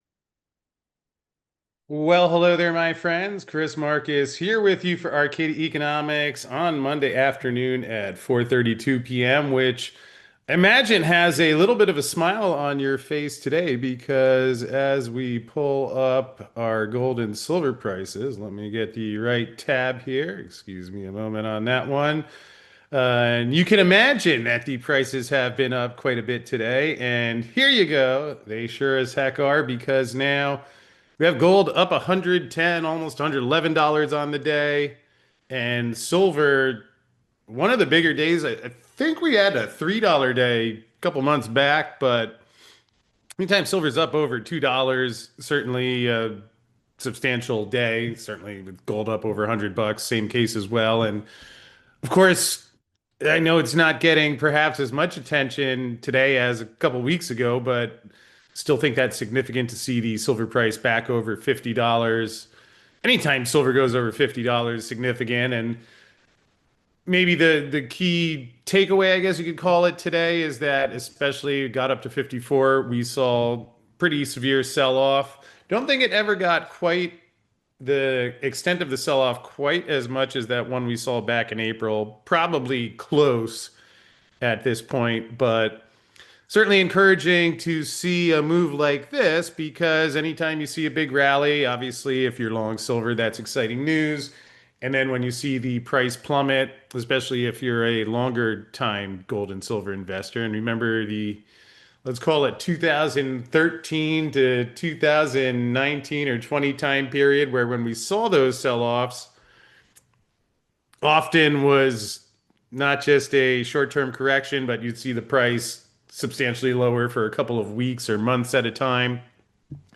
As both the spot price and the futures price are up over $2 on the day, while Wall Street is now talking about more rate cuts again. To find out the latest, and also get an update on gold (which is now up over $100 on the day), join us for this brief live update at 4:30 PM eastern!